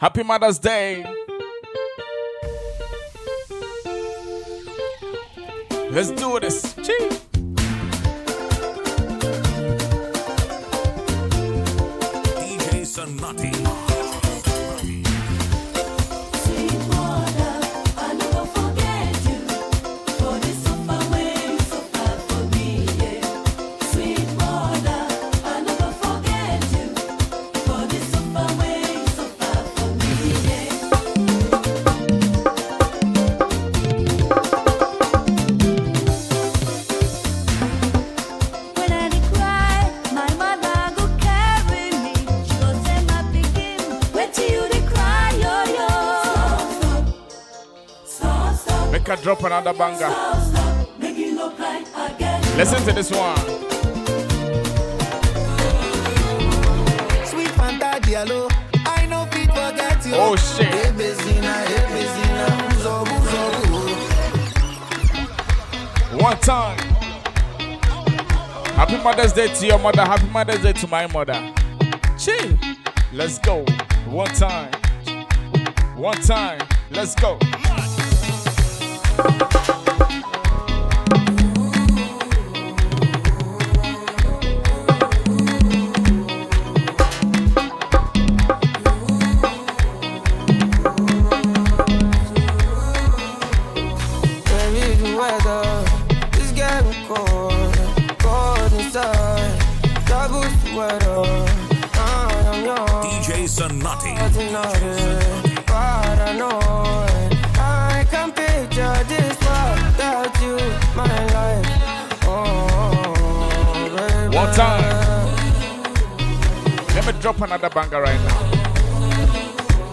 a dope Ghana music blend to honour mums.